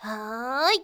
qyh蓄力佧瓦伊2.wav 0:00.00 0:00.85 qyh蓄力佧瓦伊2.wav WAV · 73 KB · 單聲道 (1ch) 下载文件 本站所有音效均采用 CC0 授权 ，可免费用于商业与个人项目，无需署名。
人声采集素材